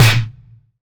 Blow Snare.wav